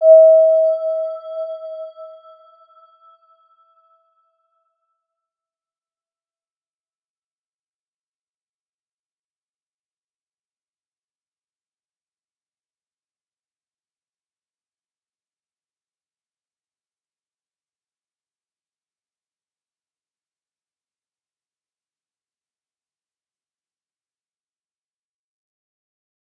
Round-Bell-E5-p.wav